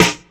• 2000s Crunchy Rap Snare Drum Sample F Key 64.wav
Royality free steel snare drum tuned to the F note. Loudest frequency: 2235Hz